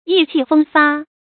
注音：ㄧˋ ㄑㄧˋ ㄈㄥ ㄈㄚ
意氣風發的讀法